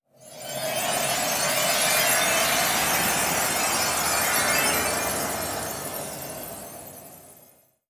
Arcane Wind Chime Gust.wav